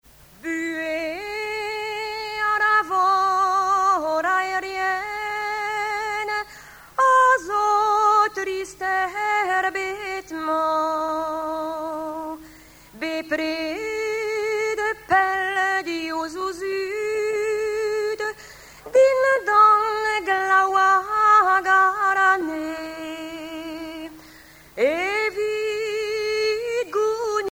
Genre strophique
Chansons de la soirée douarneniste 88
Pièce musicale inédite